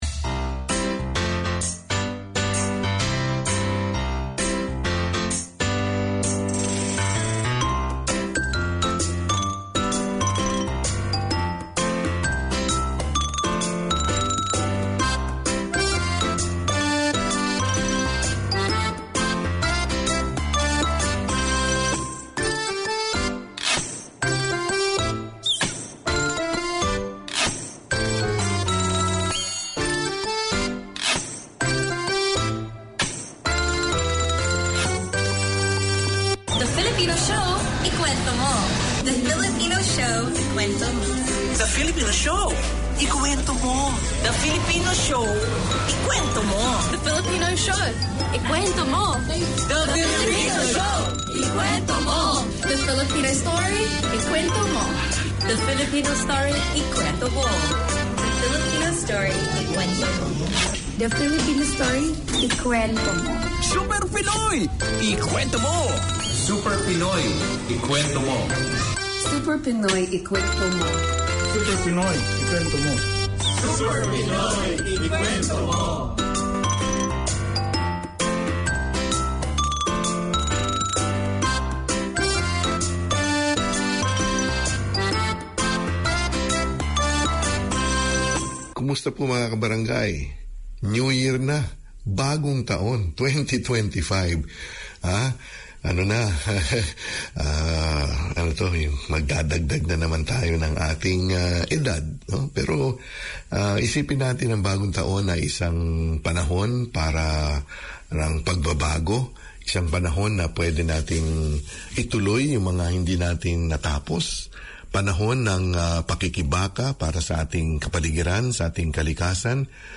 Community Access Radio in your language - available for download five minutes after broadcast.
The Filipino Show 12:40pm WEDNESDAY Community magazine Language